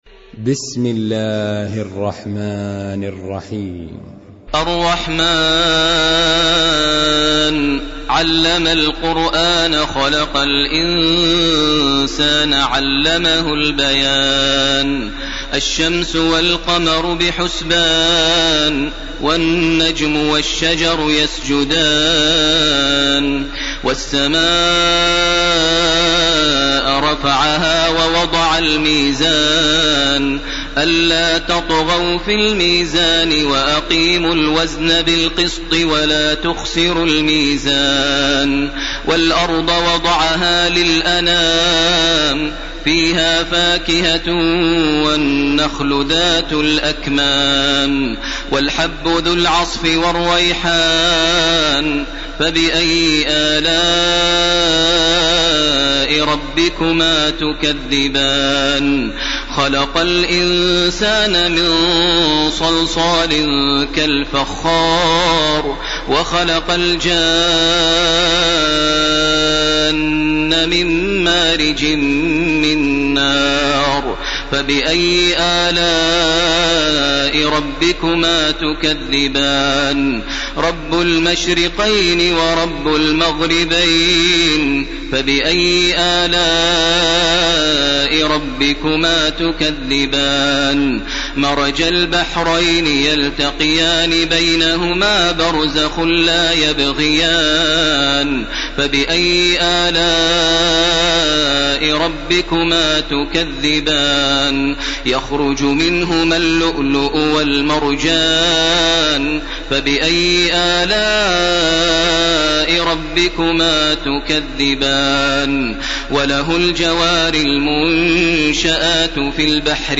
تراويح ليلة 26 رمضان 1431هـ من سور الرحمن الواقعة و الحديد Taraweeh 26 st night Ramadan 1431H from Surah Ar-Rahmaan and Al-Waaqia and Al-Hadid > تراويح الحرم المكي عام 1431 🕋 > التراويح - تلاوات الحرمين